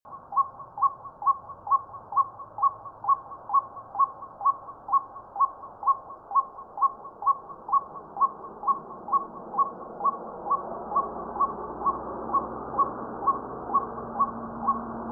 64-1-2金山2011白腹秧雞1.mp3
白腹秧雞 Amaurornis phoenicurus chinensis
錄音地點 新北市 金山區 金山
錄音環境 農田
行為描述 清晨鳴叫
錄音: 廠牌 Denon Portable IC Recorder 型號 DN-F20R 收音: 廠牌 Sennheiser 型號 ME 67